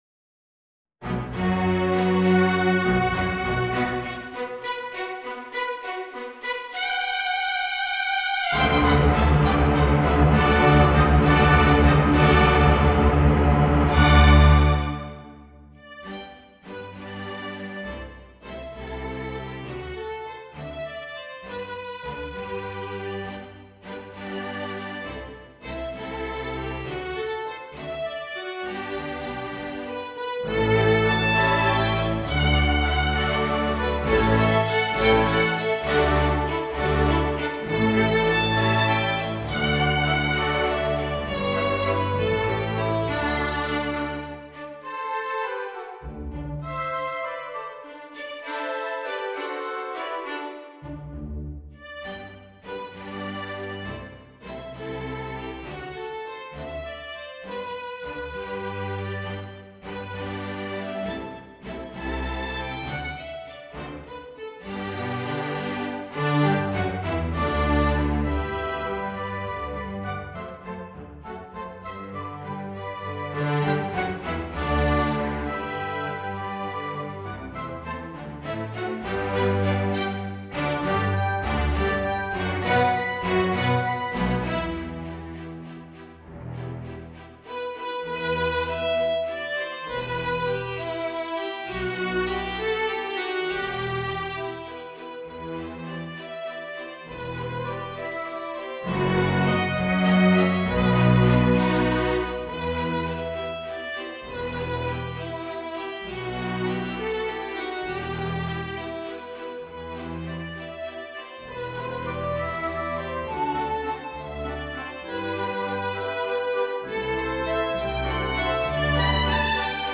This version is orchestrated.